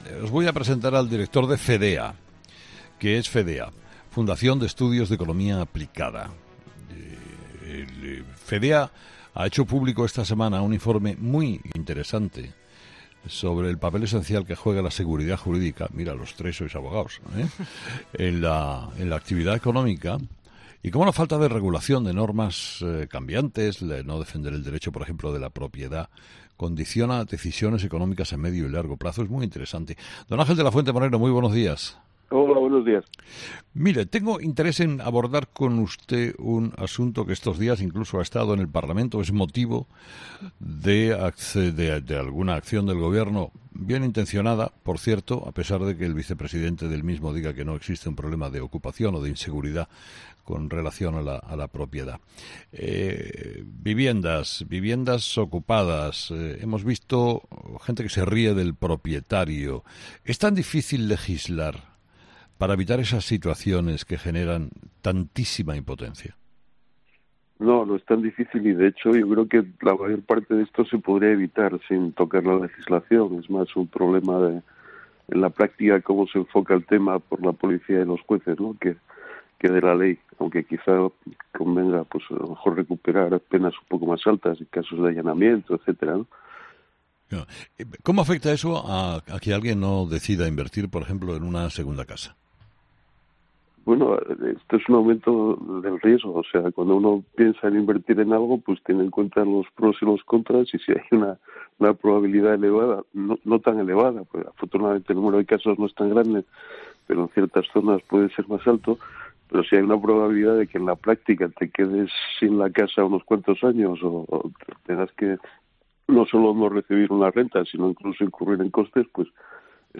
ha sido entrevistado este viernes en 'Herrera en COPE'